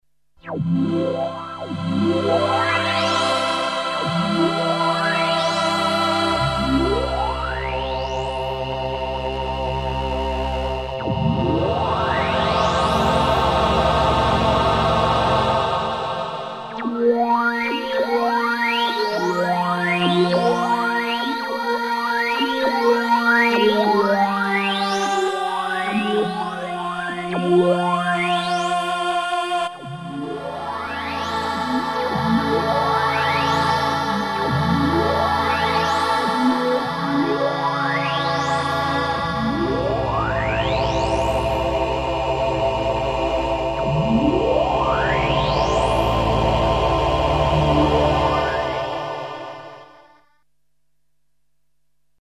formant choir